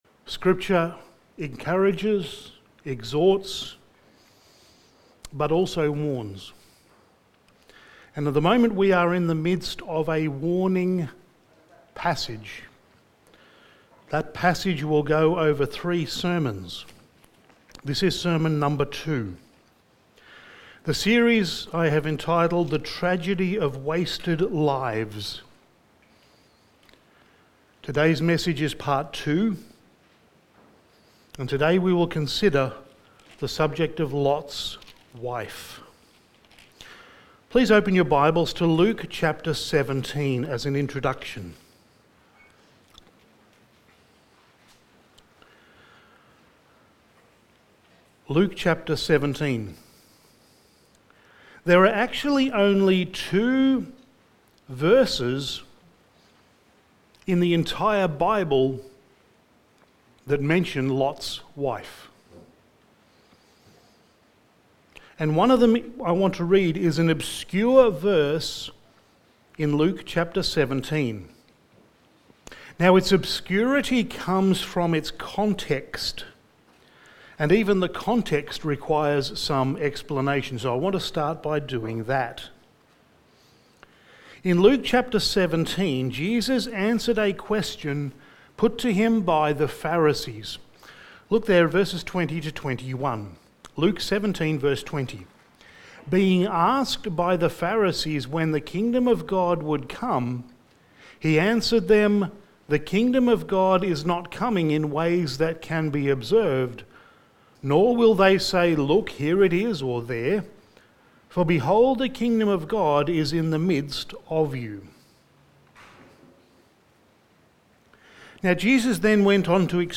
Sermon
Genesis 19:23-29 Service Type: Sunday Morning Sermon 30 « Jesus